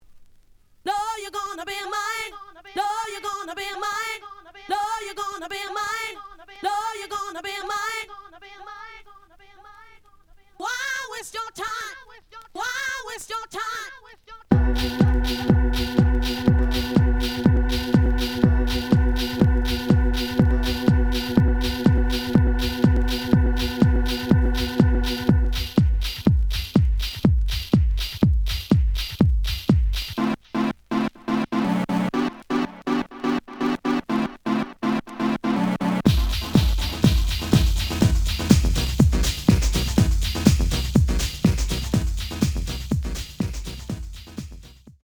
The audio sample is recorded from the actual item.
●Genre: House / Techno
Slight edge warp.